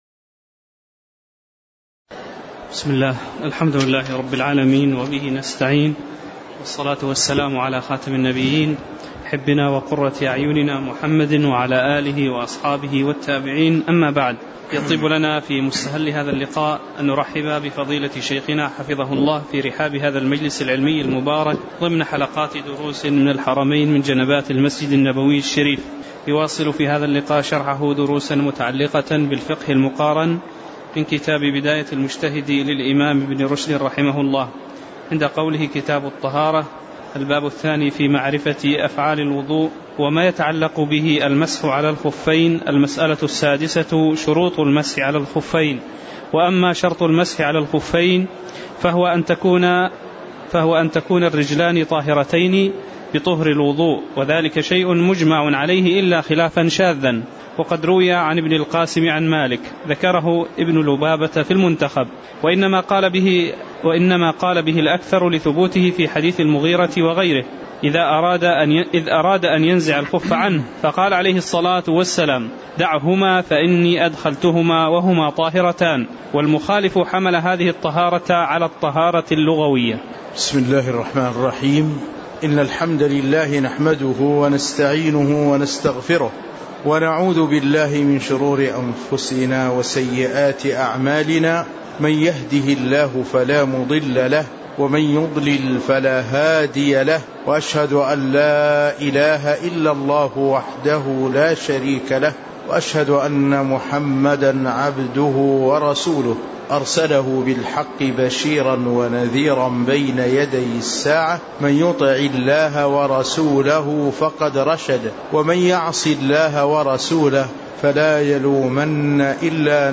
تاريخ النشر ٧ شعبان ١٤٣٩ هـ المكان: المسجد النبوي الشيخ